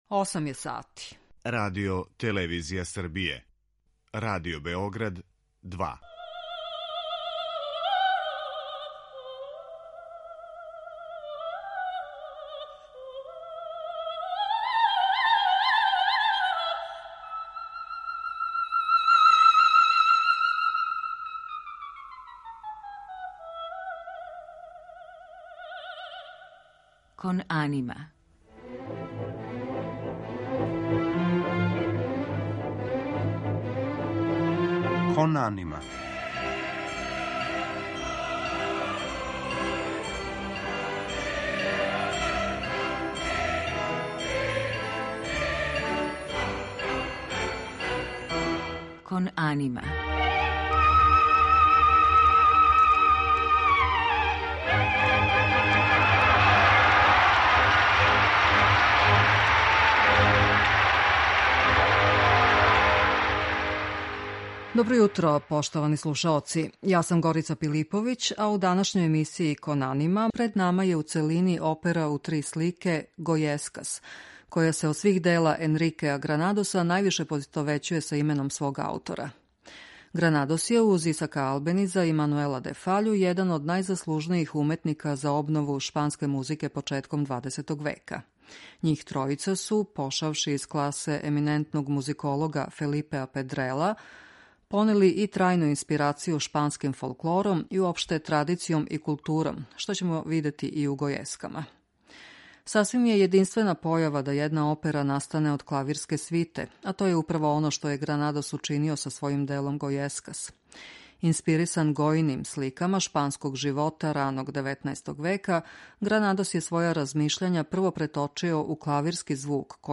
Опера Гојескас
Потом је композиција преобликована у сценско дело блиставе оркестрације.